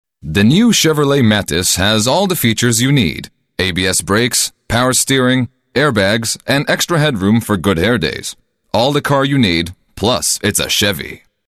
Männlich
Sprachbeispiele für Fremdsprachen
Kommerzielle Demo
Automobil